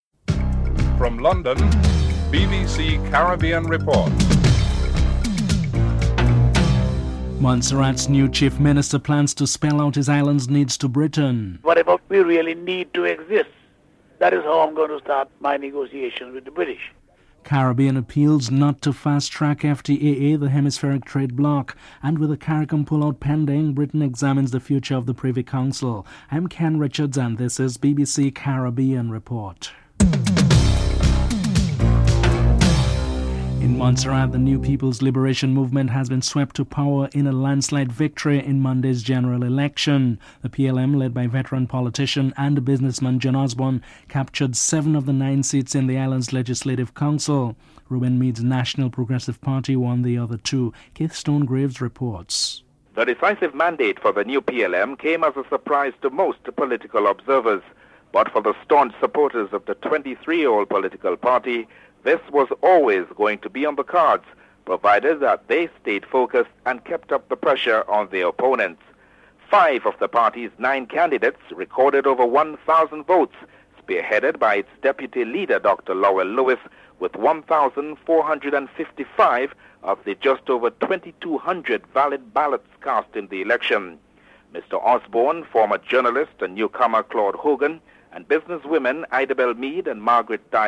1. Headlines (00:00-00:32)
2. In Montserrat, the New People's Liberation Movement has been swept into power in a landslide victory in Monday's general elections and the new Chief Minister plans to spell out his isalnd's needs to Britain. Incoming Chief Minister John Osborne and Election Supervisor Howard Fergus are interviewed.